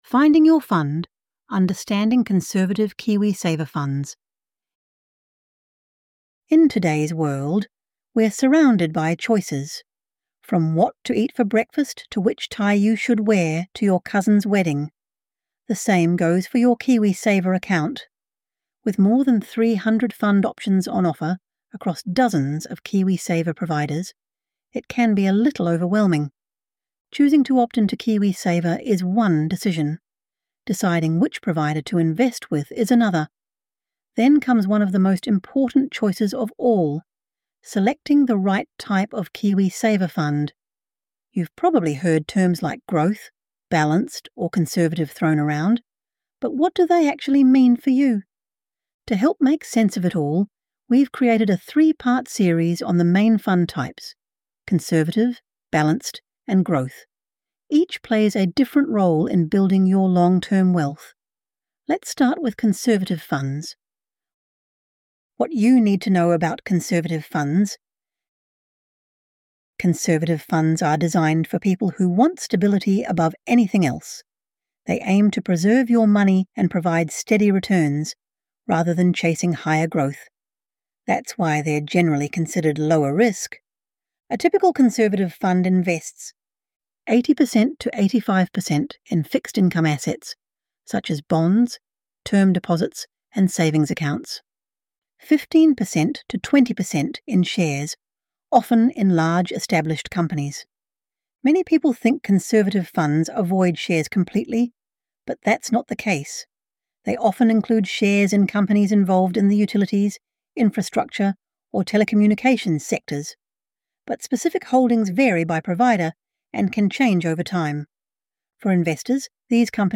This content features an AI-generated voice for narration purposes.